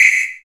SI2 SYNDRUM2.wav